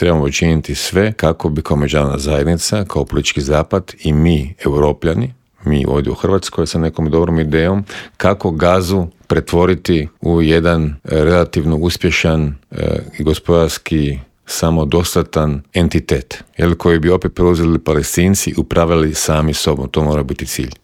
ZAGREB - Dok napetosti oko širenja Europske unije i nastavka pomoći Ukrajini traju, mađarski premijer Viktor Orban riskira i pokušava svojoj državi priskrbiti sredstva koja je Europska unija zamrznula, pojašnjava u Intervjuu Media servisa bivši inoministar Miro Kovač.